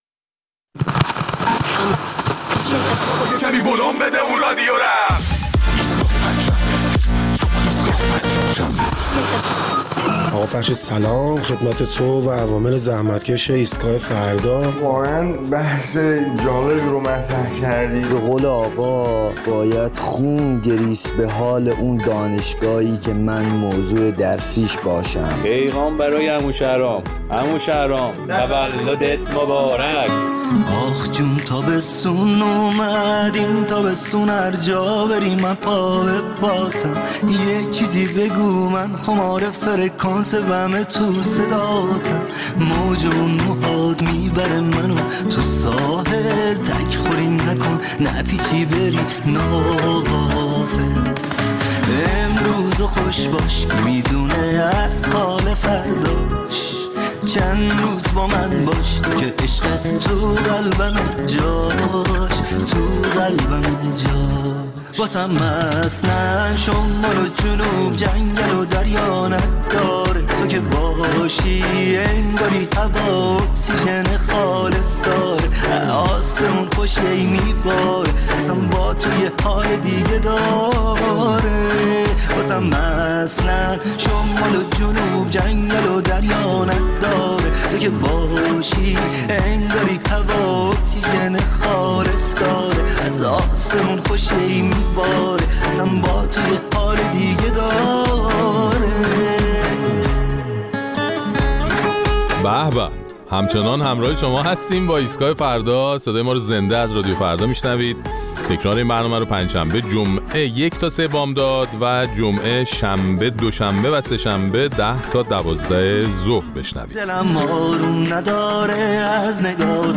در این برنامه ادامه نظرات شنوندگان ایستگاه فردا را در مورد صحبت‌های یکی از اعضای خبرگان که خواستار تدریس شیوه حکمرانی رهبر نظام شده بود می‌شنویم.